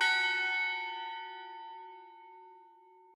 bell1_3.ogg